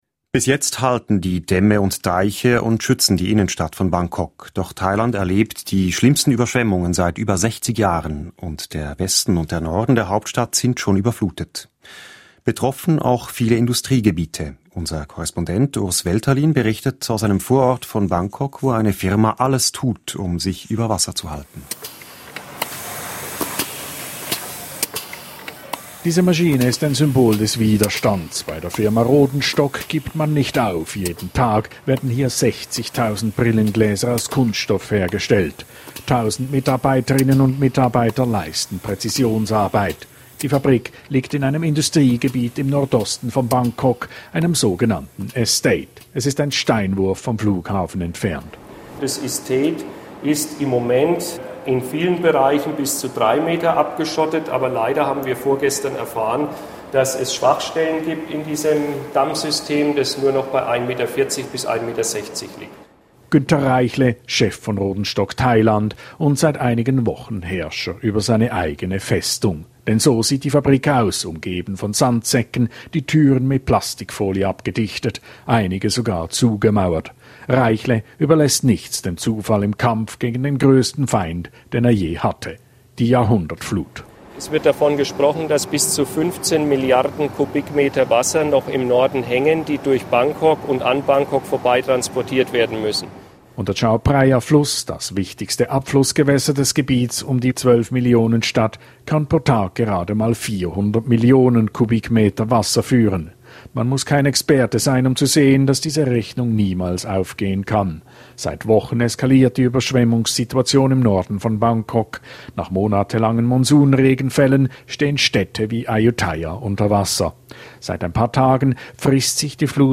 Die Reportage aus einem Vorort von Bangkok.